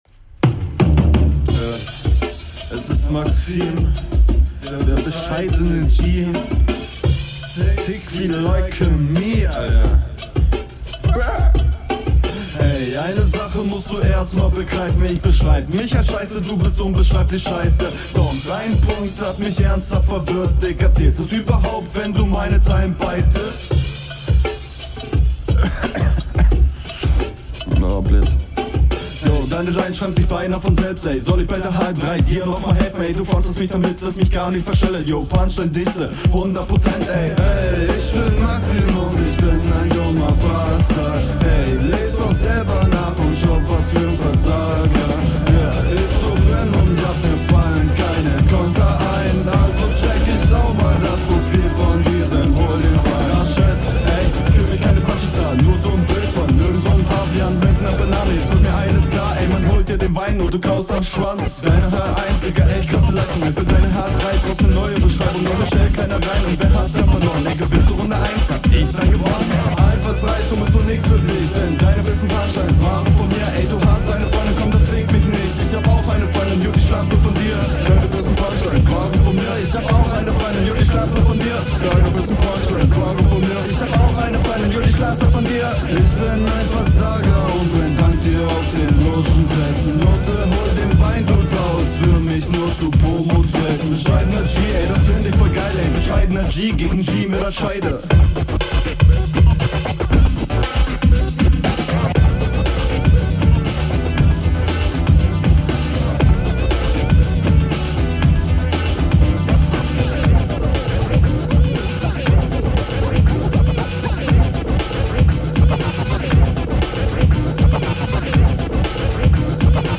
Jo singen ist jetzt nicht ganz so deins aber hab auch schlimmeres schon gehört.
Den Stimmeinsatz finde ich okay, er delivert nicht besonders krass, der Flow ist soweit im …
Einstieg kommt richtig smooth.